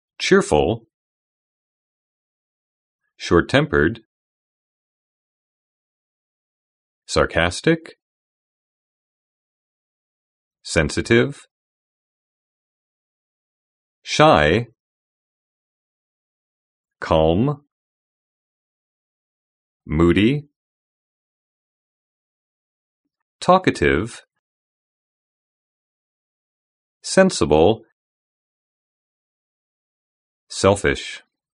Now listen to these words being pronounced.